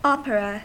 Ääntäminen
US : IPA : [ˈoʊ.pər.ə]